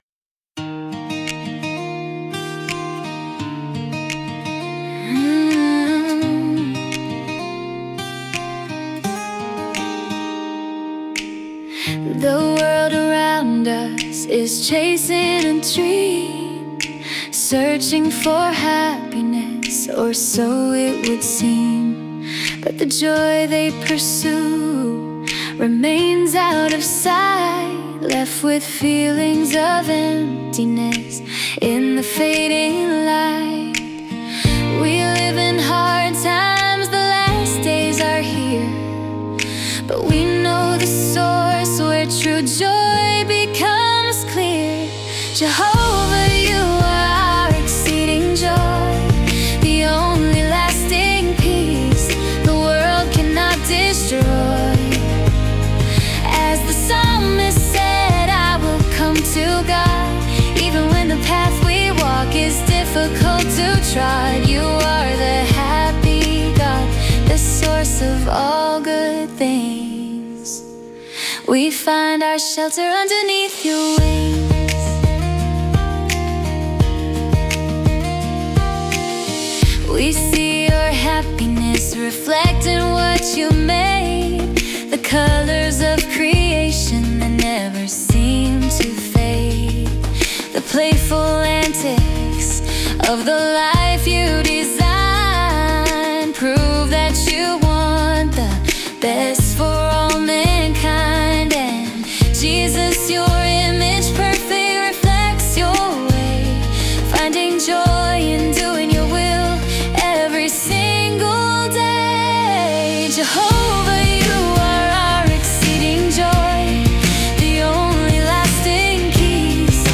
My AI Created Music